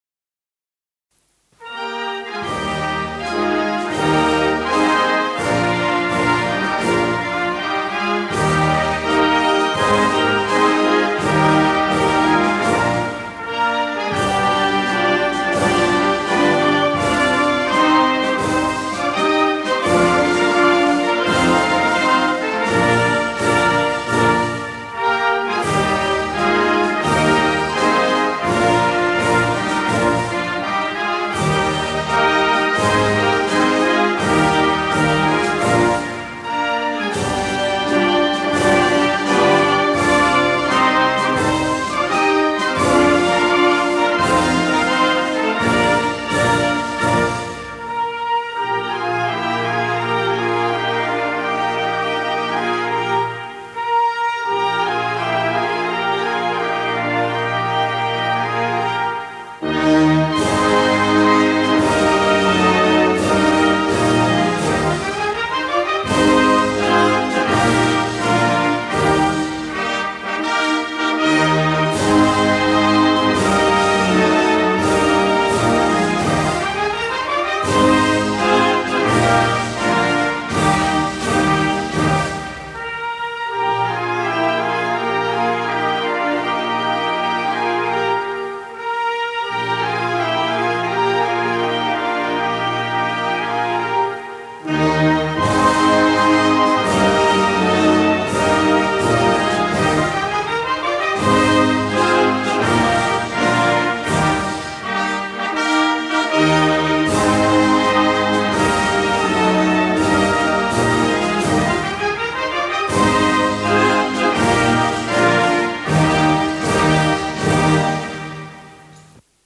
I sovrani uscirono dalla porta di mare , fra ali di soldati che gridavano “ Viva ‘o Re” e la banda che suonava l’inno borbonico.